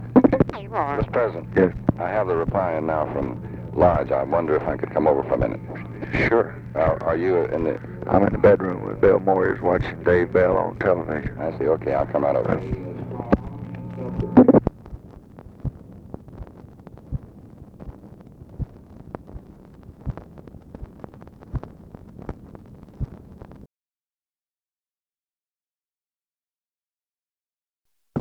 Conversation with DEAN RUSK, February 4, 1966
Secret White House Tapes